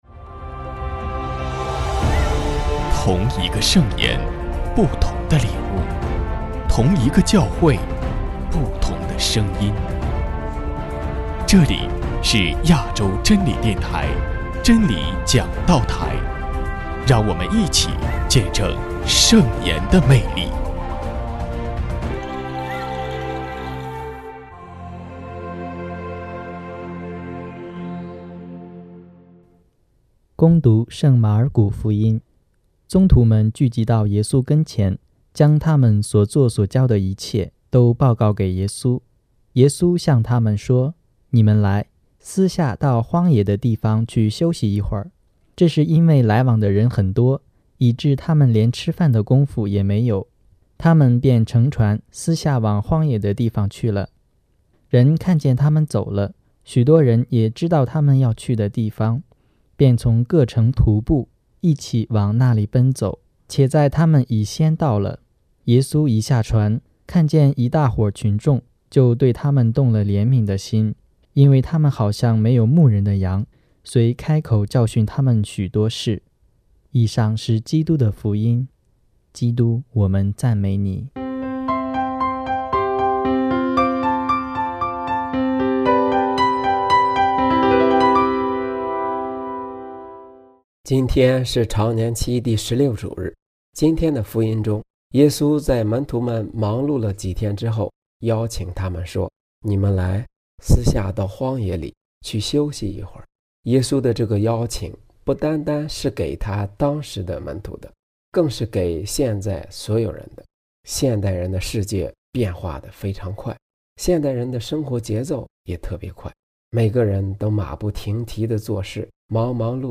证道